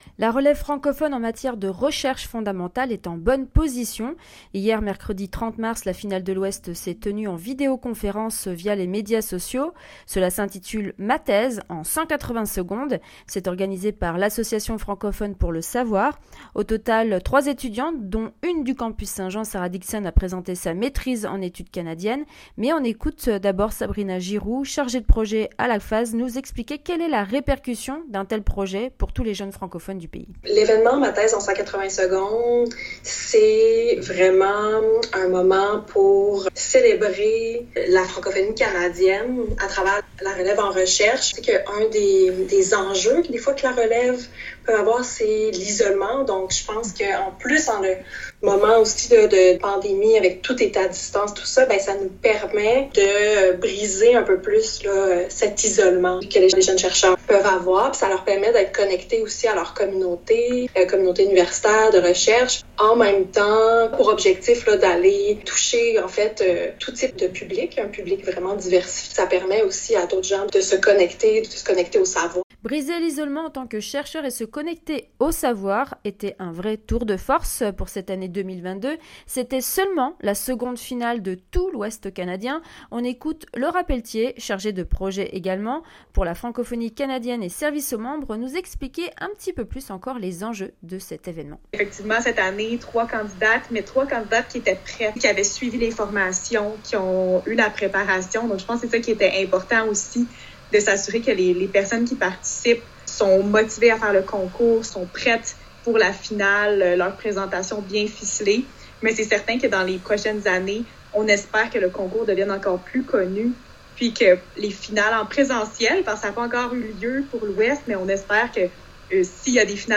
La finale de l'Ouest a eu lieu cette semaine. On en parle au micro de Nord Ouest FM.